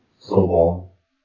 speech
speech-commands